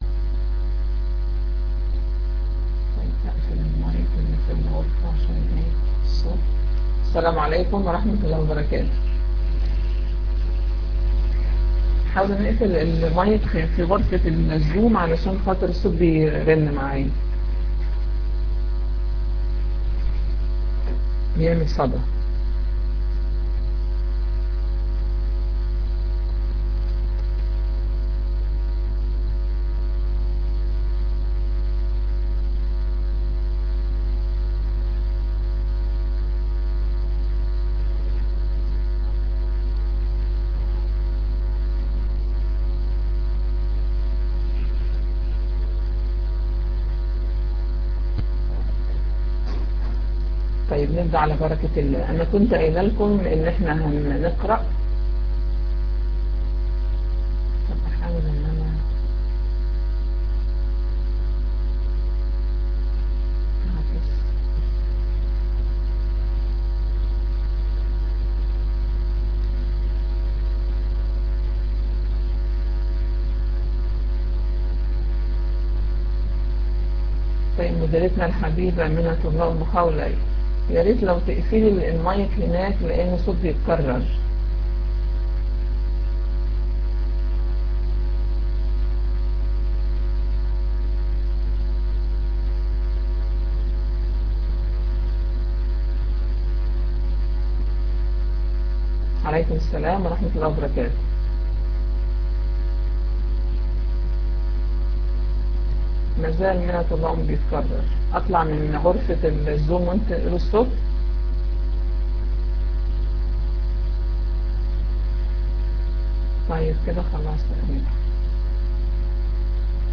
الدرس الخامس.mp3